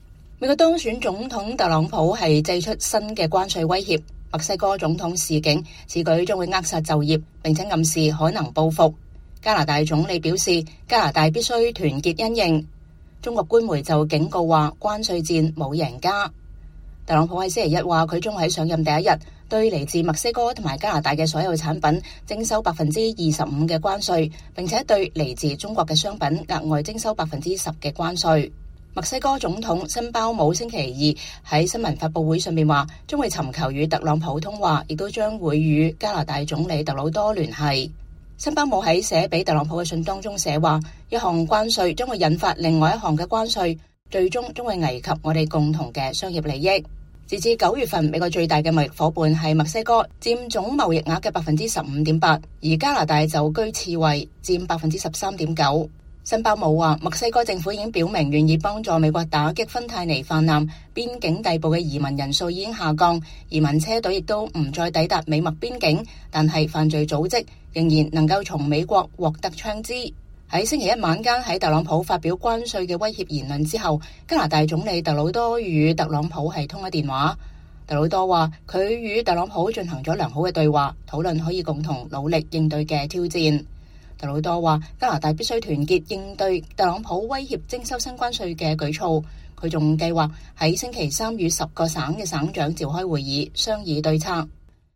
墨西哥總統克勞蒂亞辛鮑姆在記者會上宣讀致特朗普的信函，並警告關稅將導致兩國通貨膨脹和失業率上升。